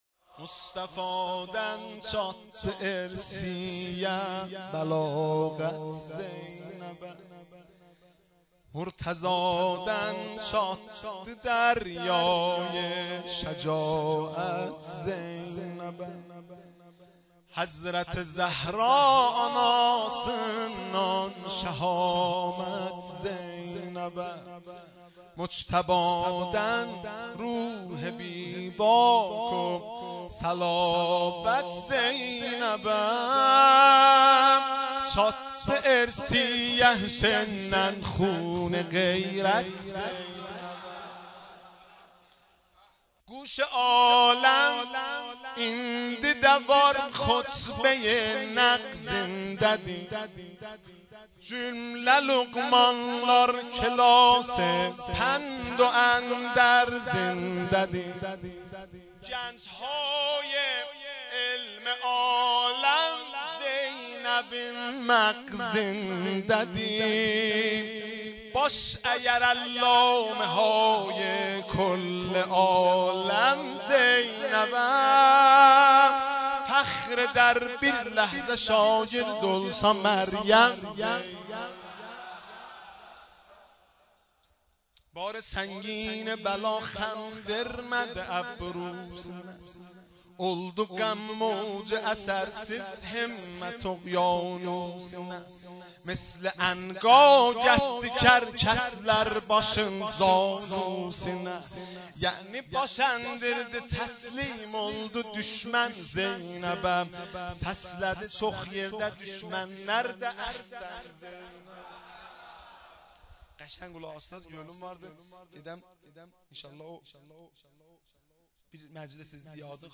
مولودی ترکی میلاد حضرت زینب کبری سلام الله علیها